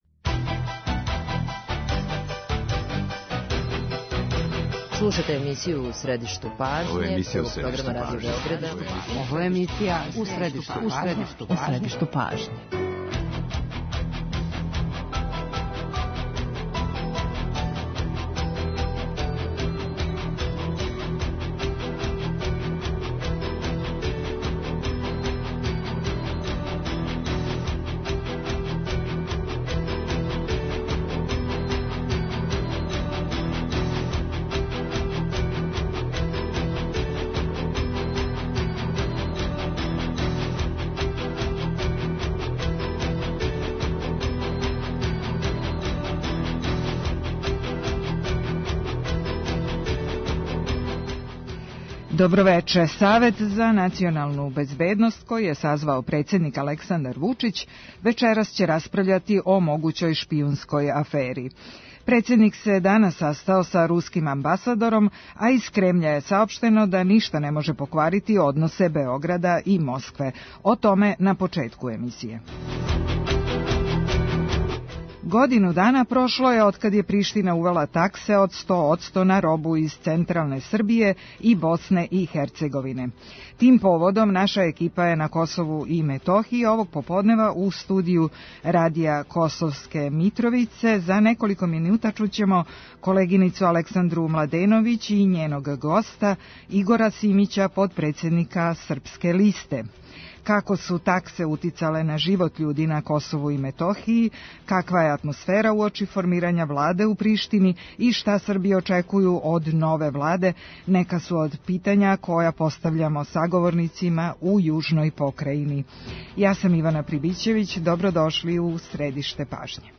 Пре тачно годину дана Приштина је увела таксе од 100 одсто на робу из централне Србије и Босне и Херцеговине. Како се одвија живот у тим околностима – истражује екипа Радио Београда на лицу места.